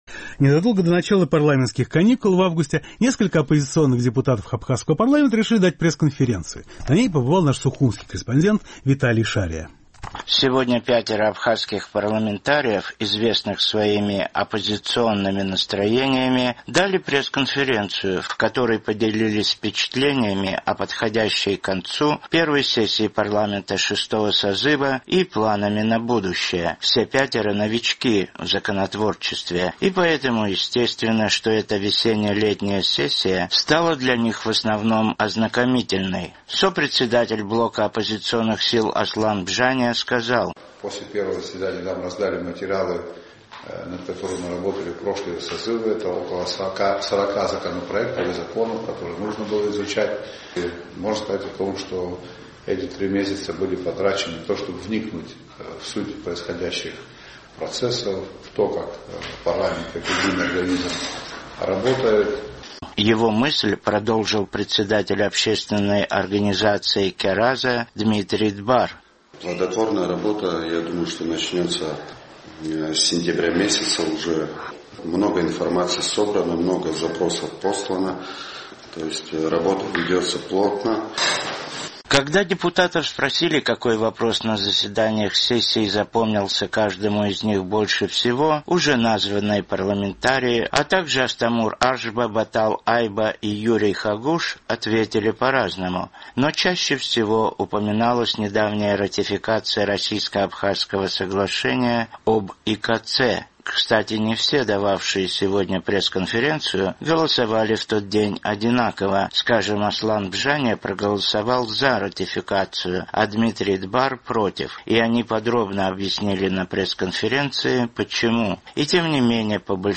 Сегодня пятеро абхазских парламентариев, известных своими оппозиционными взглядами, дали пресс-конференцию, в которой поделились впечатлениями о подходящей к концу первой сессии парламента шестого созыва и планами на будущее.